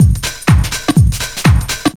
B4HOUSE124.wav